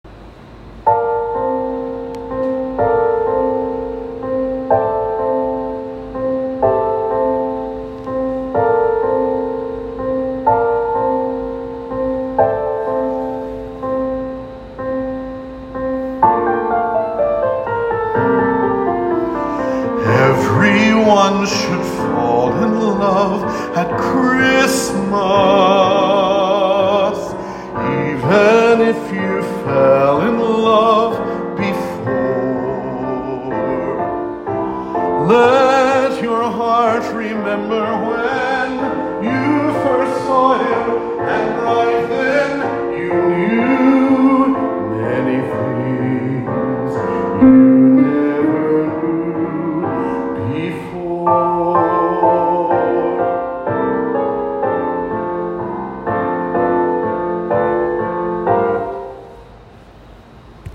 Everyone Should Fall in Love at Christmas – Anna Vocal DemoDownload